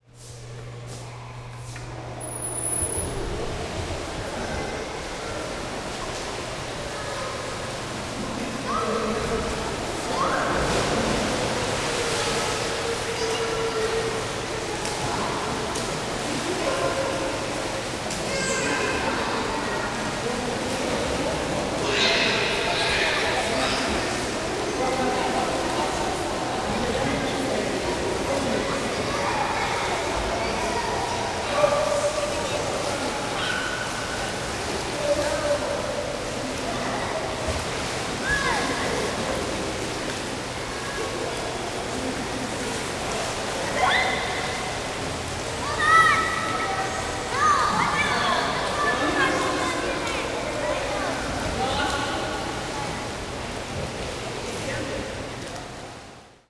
Indoor swimming pool creating a diffuse sound field
Pool.aiff